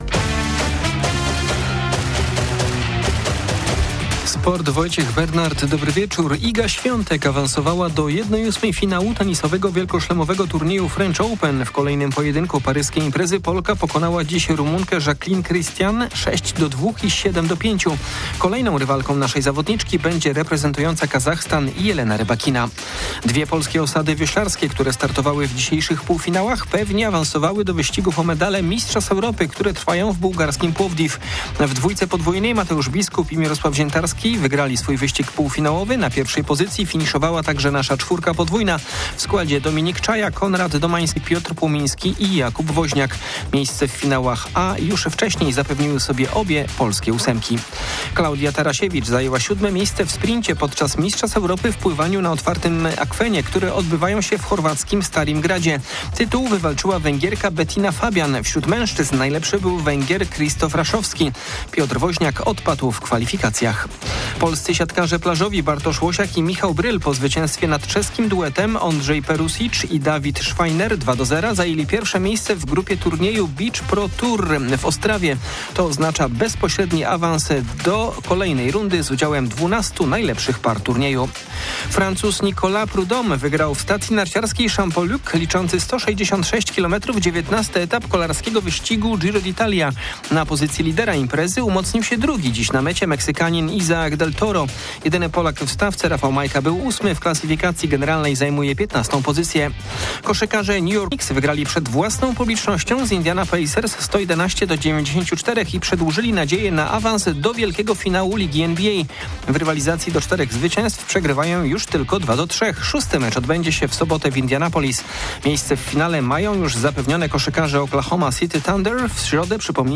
30.05.2025 SERWIS SPORTOWY GODZ. 19:05